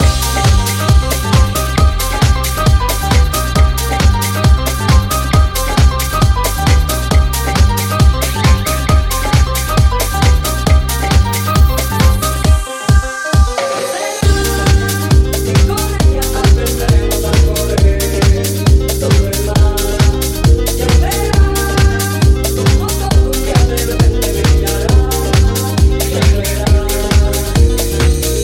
piano house classics
Genere: house, piano house, anni 90, successi, remix